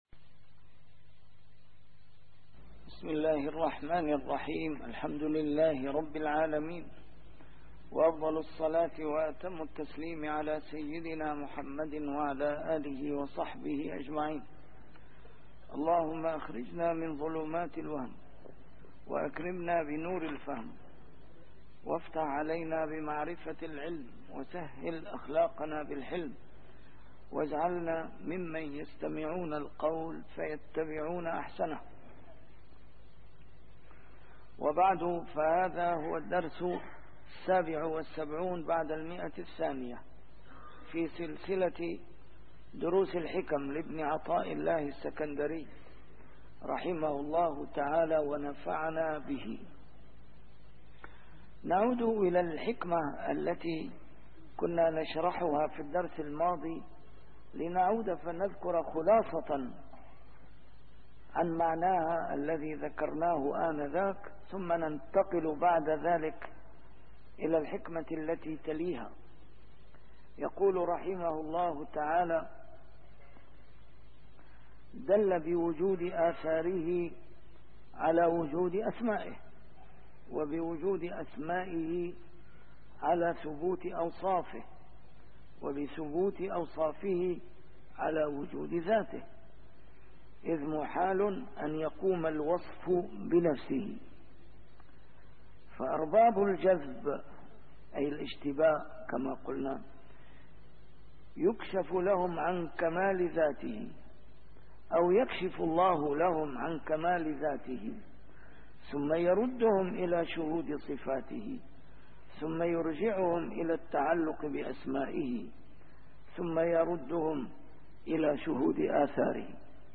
A MARTYR SCHOLAR: IMAM MUHAMMAD SAEED RAMADAN AL-BOUTI - الدروس العلمية - شرح الحكم العطائية - الدرس رقم 277 شرح الحكمة رقم 250 (تتمة) - 251